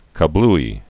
(kə-blē) Slang